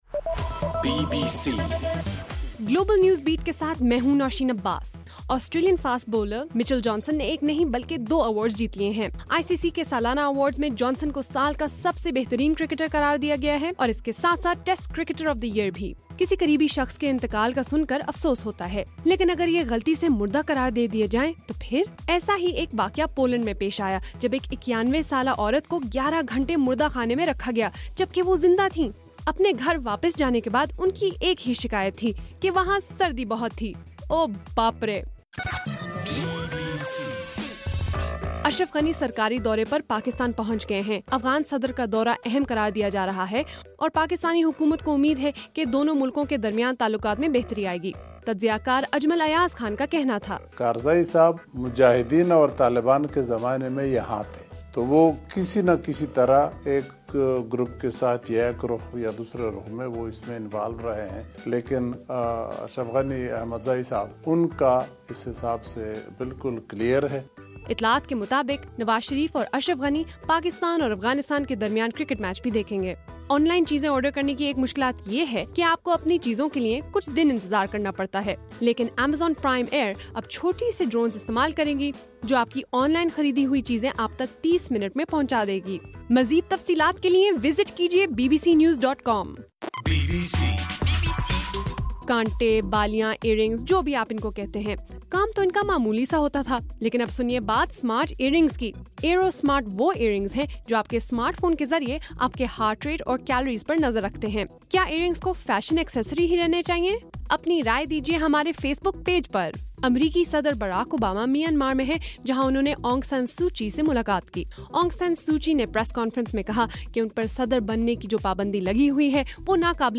10 نومبر: رات 8 بجے کا گلوبل نیوز بیٹ بُلیٹن